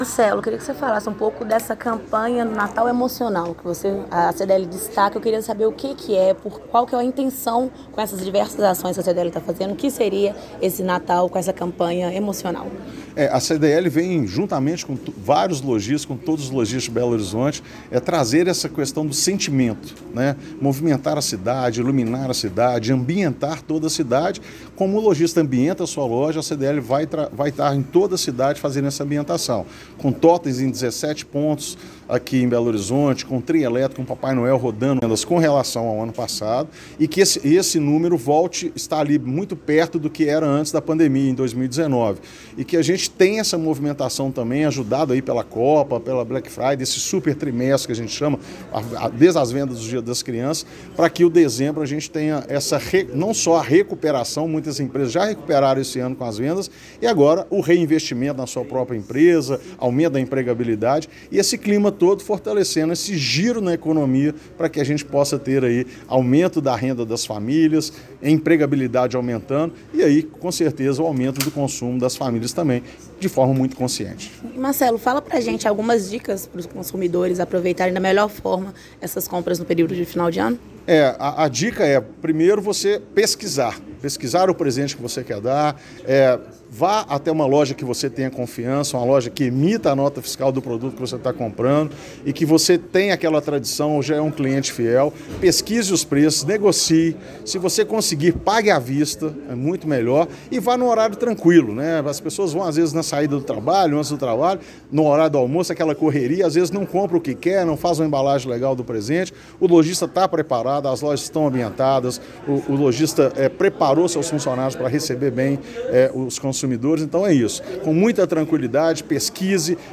Coletiva-de-Imprensa-Acoes-de-Natal-1
Coletiva-de-Imprensa-Acoes-de-Natal-1.mp3